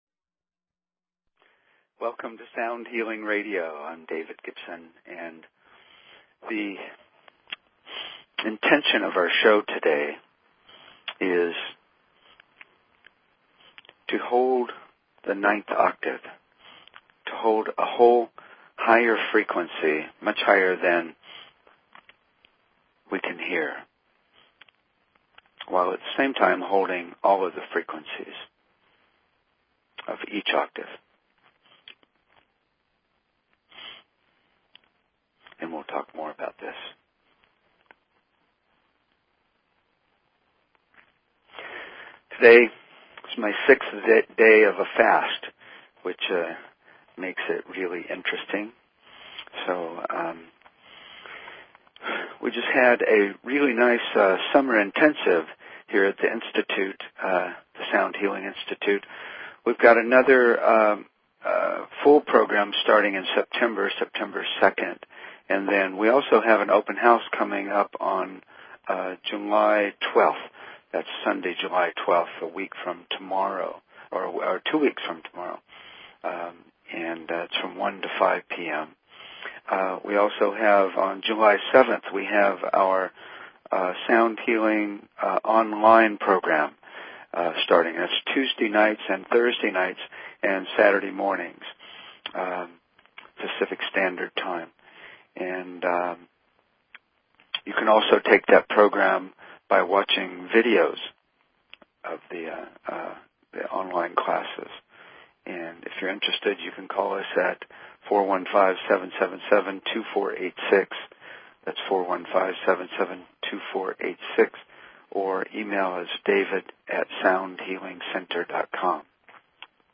Talk Show Episode, Audio Podcast, Sound_Healing and Courtesy of BBS Radio on , show guests , about , categorized as
We take you through the whole meditation and resonate the 9th octave.